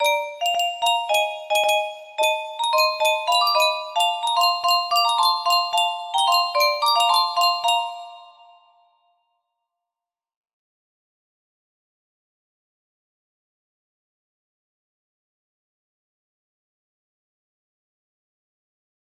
Gaudeamus Igitur music box melody
Grand Illusions 30 (F scale)